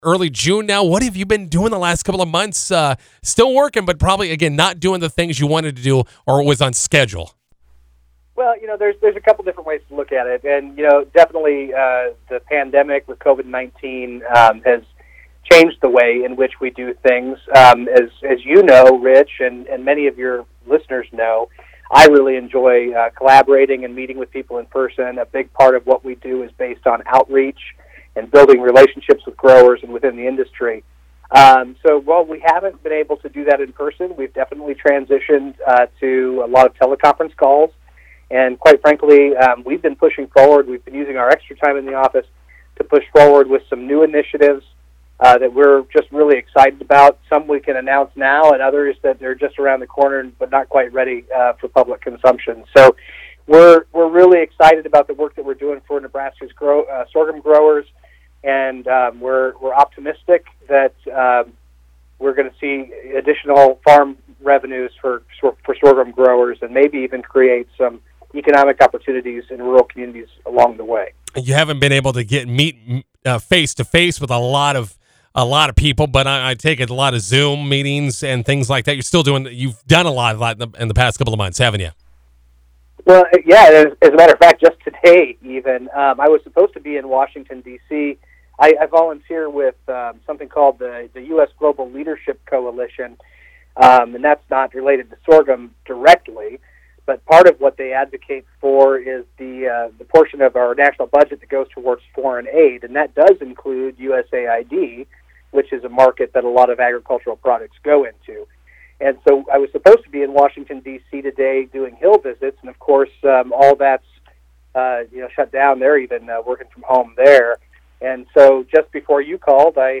INTERVIEW: Potential entrepreneurs are being encouraged to develop a sorghum-based product that would be made in Nebraska. Find out how a program called “Catalyst” seeks to support this effort.